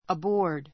aboard əbɔ́ː r d ア ボ ー ド 副詞 船[飛行機・列車・バス]に乗って, 船内[機内・車内]に go aboard go aboard （船などに）乗り込 こ む All aboard!